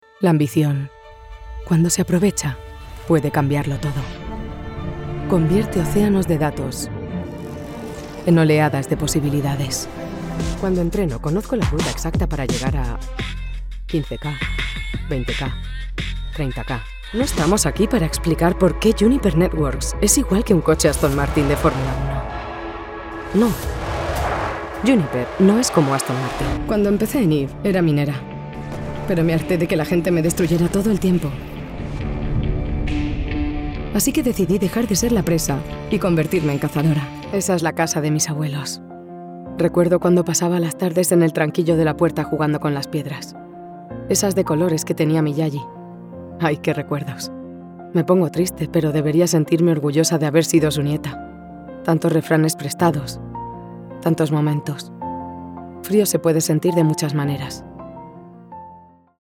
European Spanish female voice over talent at your disposal!
I have a current, relatable, on trend voice, that is bright, clear, informative and engaging.
Iberian Spanish accent. 🙂